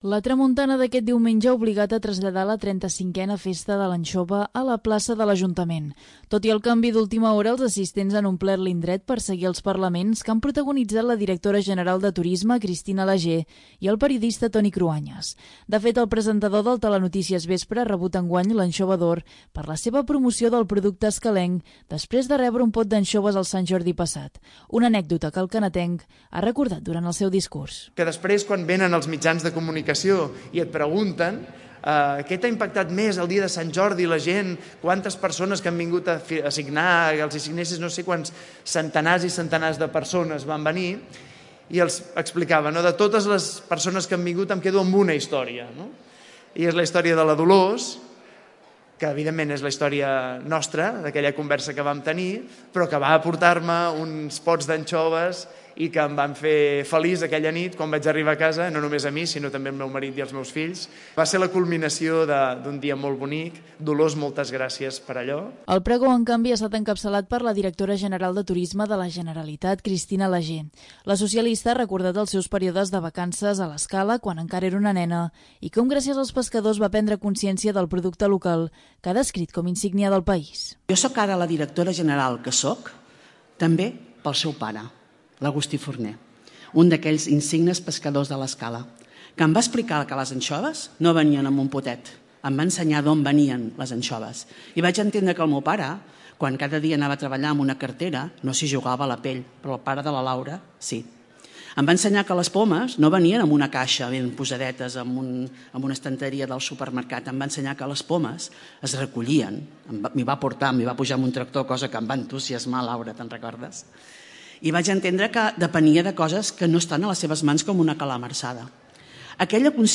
El periodista Toni Cruanyes, com a Anxova d'Or, i la directora general de Turisme Cristina Lagé, com a pregonera, han estat els protagonistes de la 35a Festa de l'Anxova, que s'ha celebrat aquest diumenge. A causa de la tramuntana, l'esdeveniment s'ha traslladat a la plaça de l'Ajuntament, on més enllà dels parlaments, s'ha realitzat una cantada d'havaneres i una degustació popular d'anxoves amb més de 2.000 tiquets venuts.
Tot i el canvi d'última hora, els assistents han omplert l'indret per seguir els parlaments, que han protagonitzat la directora general de Turisme, Cristina Lagé, i el periodista, Toni Cruanyes.